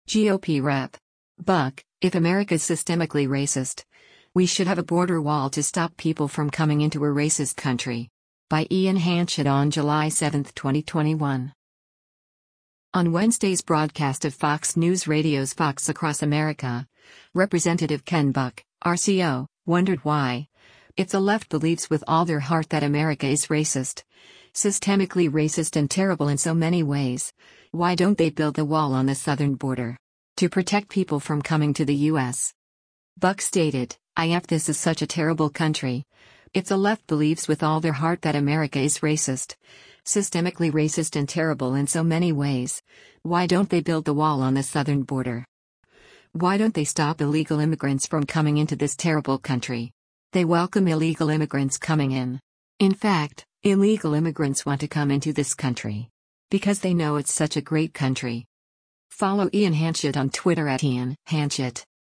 On Wednesday’s broadcast of Fox News Radio’s “Fox Across America,” Rep. Ken Buck (R-CO) wondered why, “if the left believes with all their heart that America is racist, systemically racist and terrible in so many ways, why don’t they build the wall on the southern border?” to protect people from coming to the U.S.